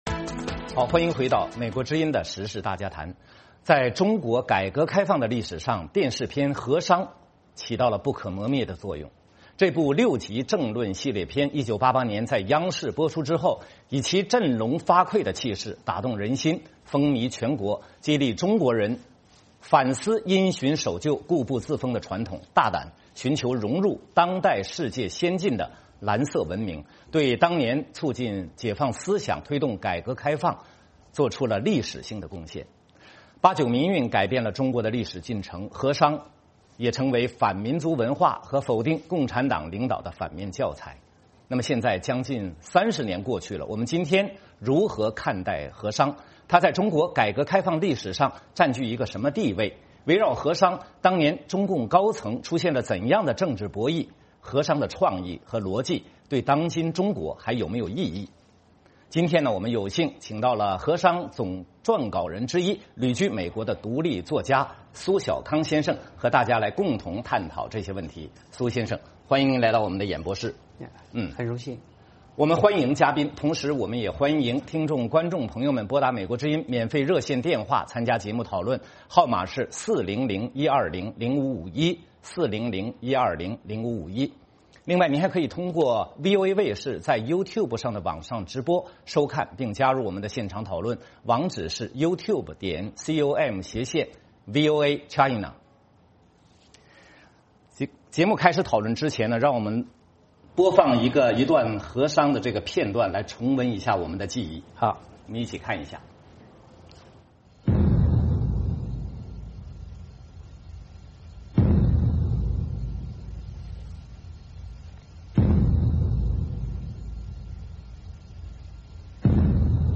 时事大家谈：专访苏晓康：风雨30载话《河殇》